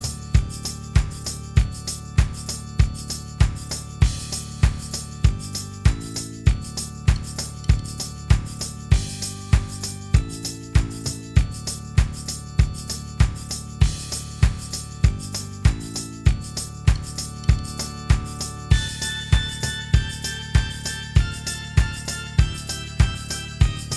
Minus Main Guitar Pop (2010s) 2:58 Buy £1.50